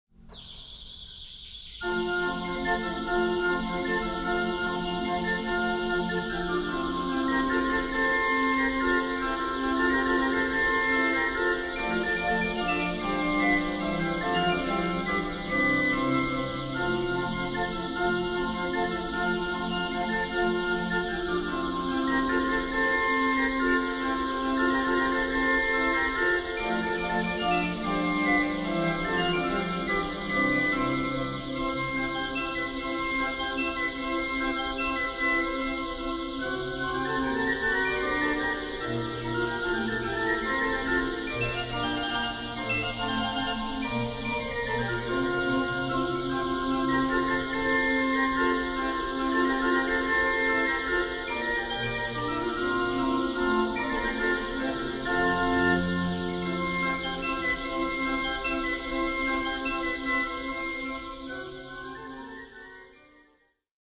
Orgelmusik aus Klassik und Frühromantik